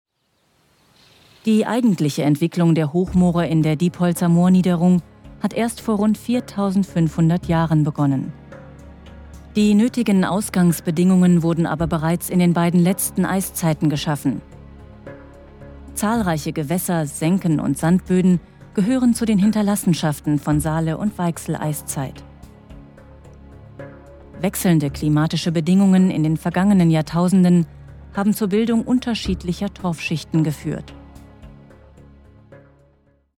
Sprechprobe: Werbung (Muttersprache):
german female voice over talent